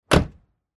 Звук захлопнутой двери автомобиля